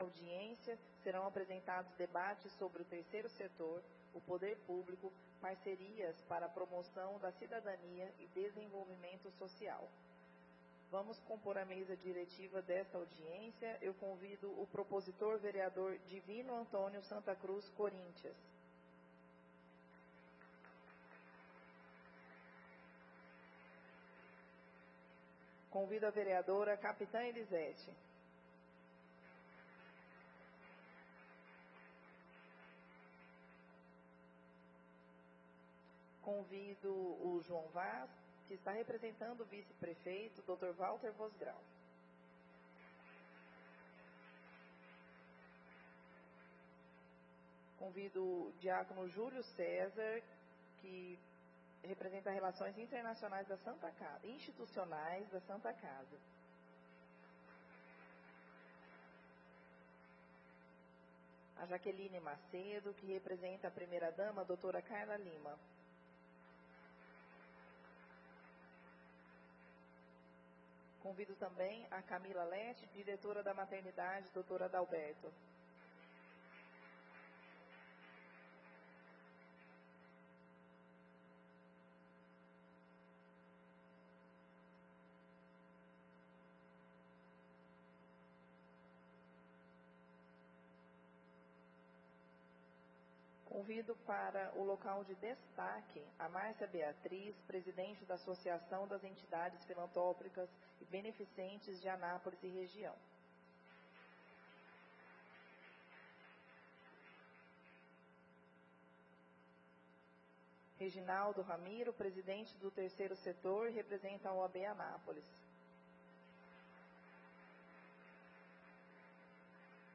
Audiência Pública - Tema Terceiro Setor e Poder Publico. Dia 24/04/2025